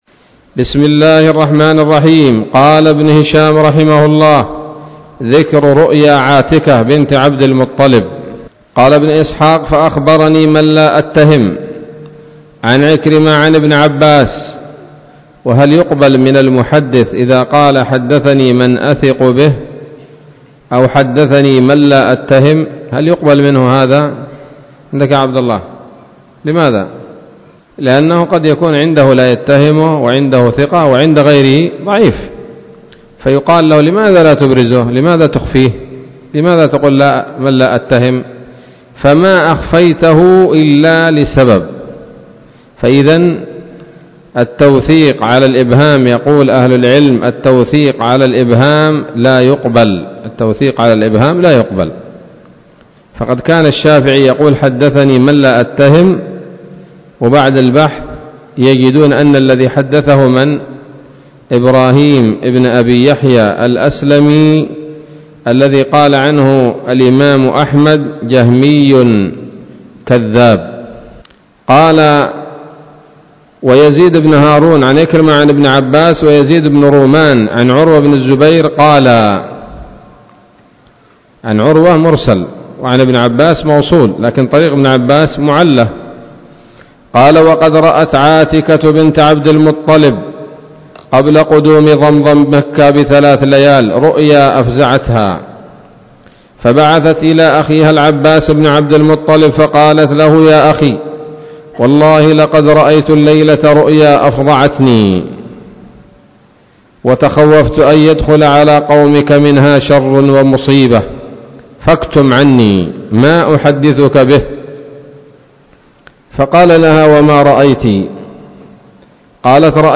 الدرس الثامن بعد المائة من التعليق على كتاب السيرة النبوية لابن هشام